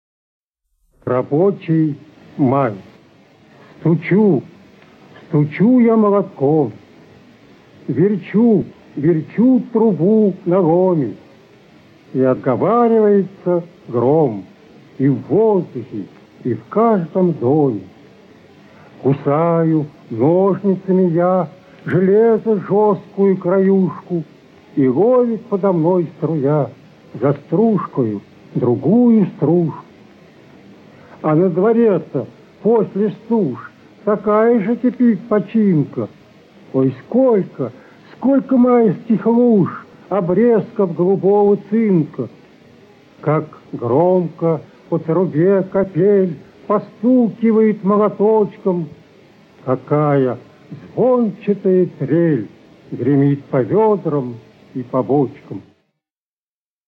1. «Василий Казин – Рабочий май (читает автор)» /
vasilij-kazin-rabochij-maj-chitaet-avtor